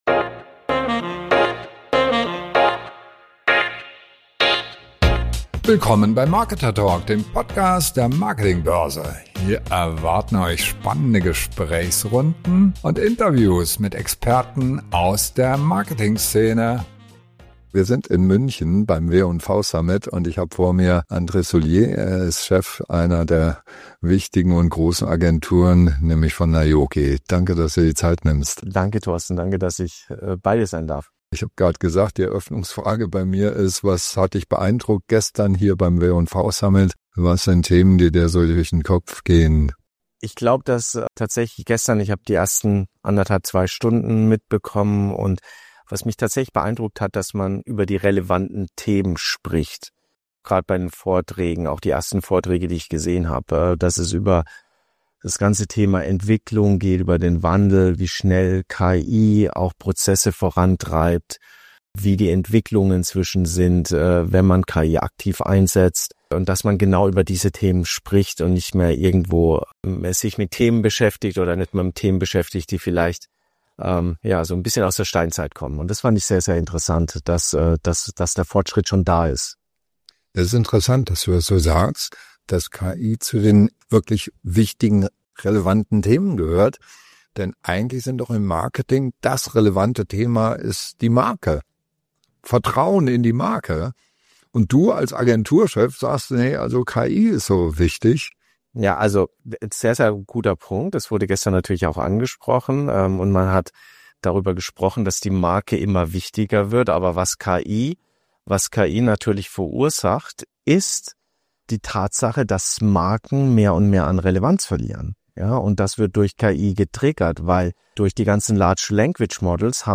Marken verlieren an Relevanz – und KI ist schuld. Was müssen Marketing-Manager jetzt tun? Ein ehrliches Gespräch über das Ende der alten Spielregeln im Marketing, die Rückkehr zur Marke und den versteckten Vorteil von synthetischen Zielgruppen.